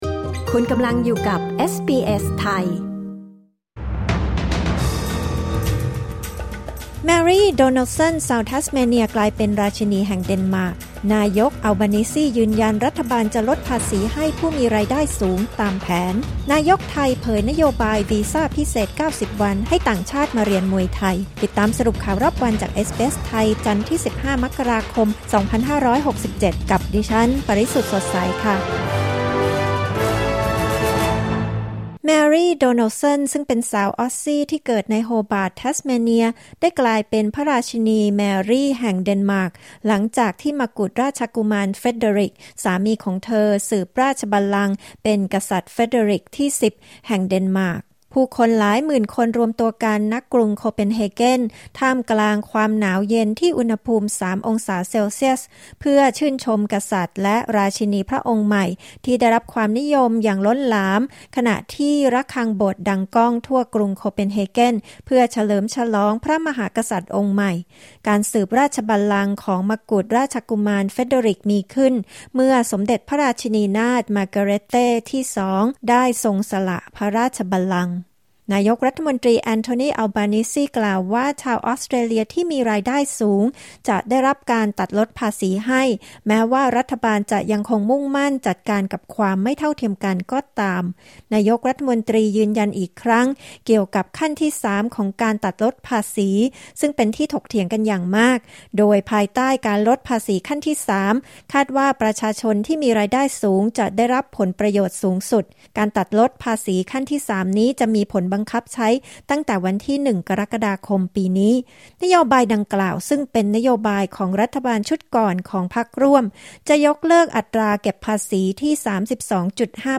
สรุปข่าวรอบวัน 15 มกราคม 2567